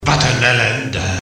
Rapsong